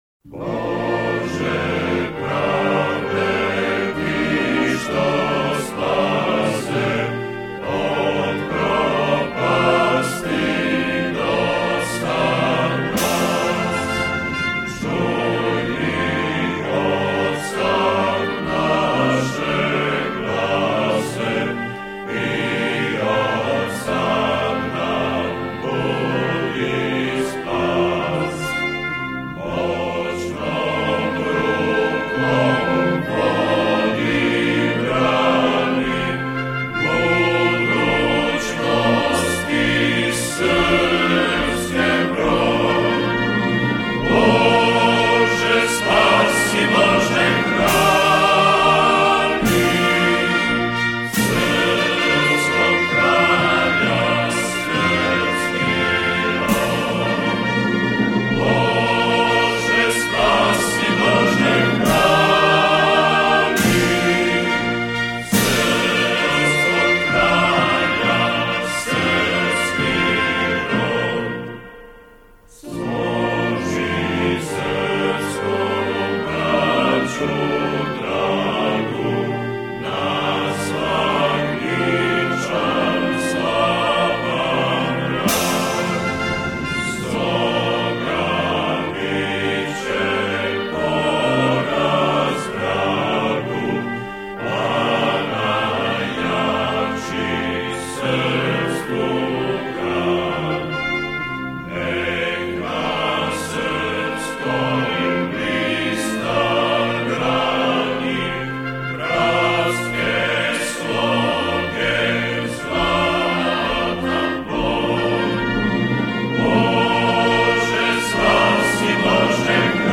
скачать mp3 (хор)